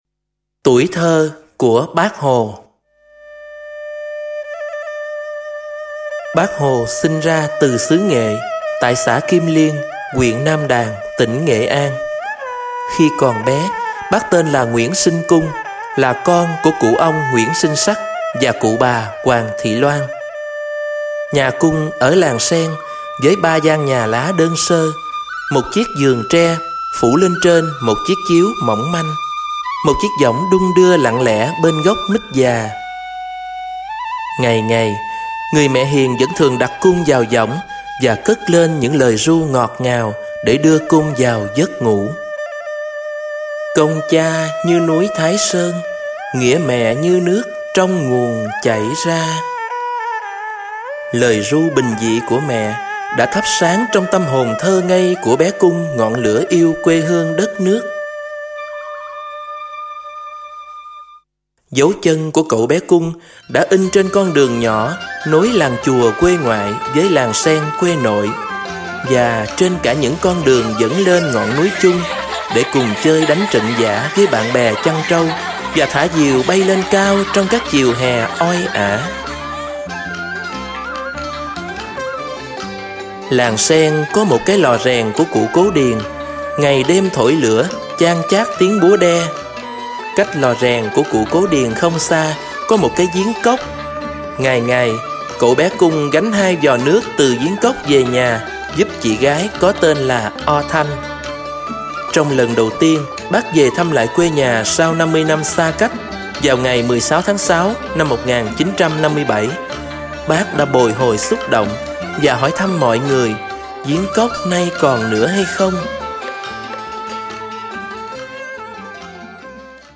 Sách nói | Tuổi thơ của Bác Hồ